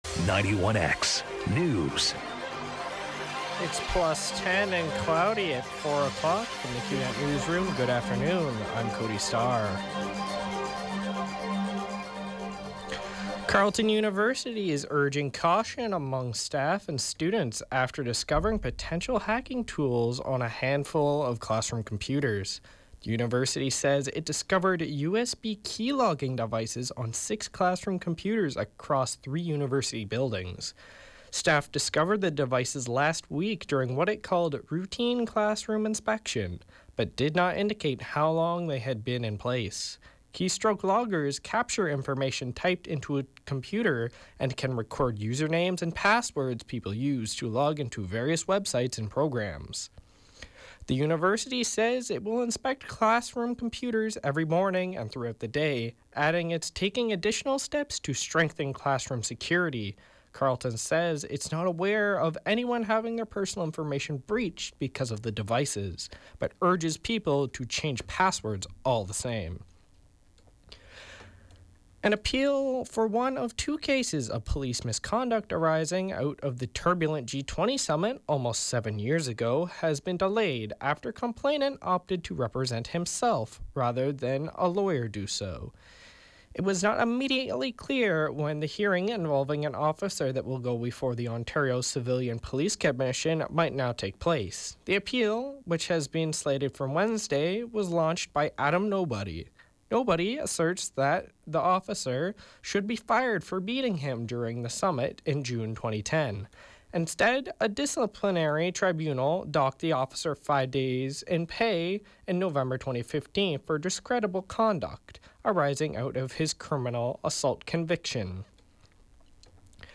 91X FM Newscast — Tuesday March 28, 2017, 4 p.m.